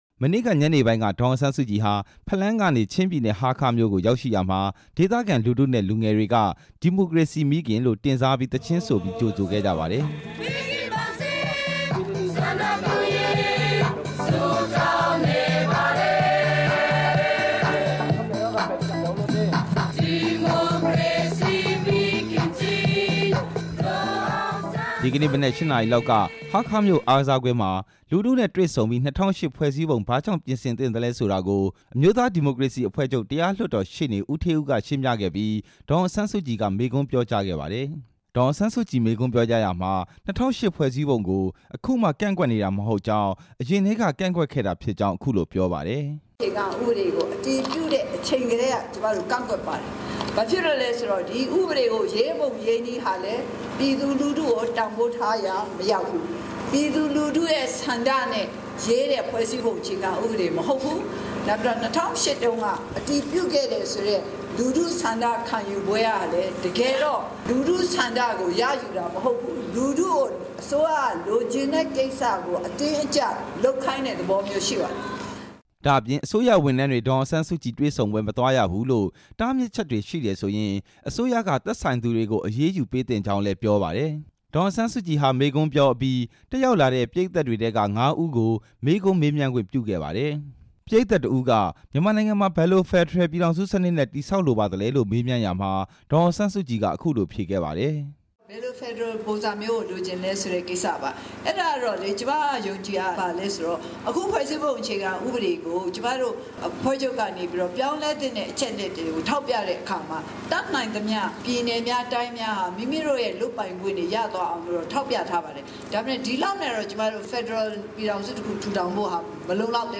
ဒီကနေ့ ချင်းပြည်နယ် ဟားခါးမြို့မှာ ကျင်းပတဲ့ ၂၀၀၈ ခုနှစ် ဖွဲ့စည်းပုံအခြေခံဥပဒေကို ပြင်ဆင်မလား၊ အသစ်ရေးဆွဲမလားဆိုတဲ့ လူထုဆန္ဒခံယူပွဲမှာ ပရိတ်သတ်တစ်ဦးက မေးမြန်းစဉ် ဒေါ်အောင်ဆန်း စုကြည် ပြန်လည် ဖြေကြားခဲ့တာပါ။